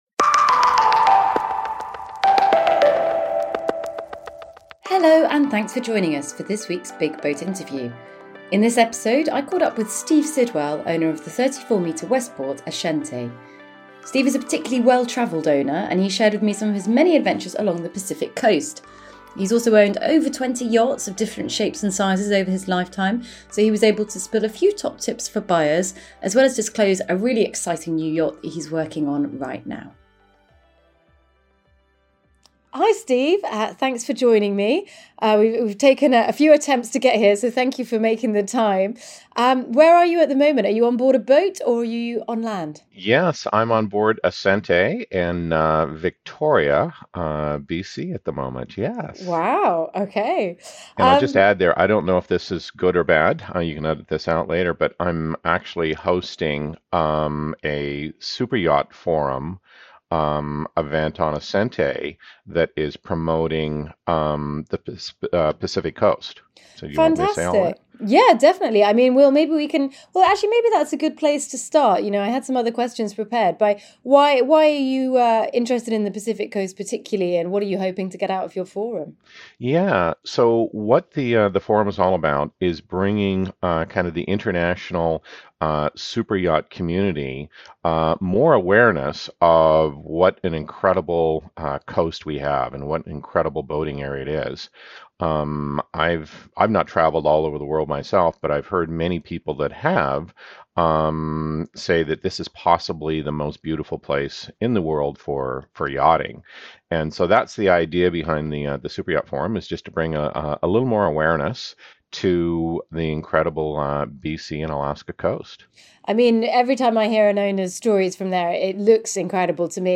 The Big BOAT Interview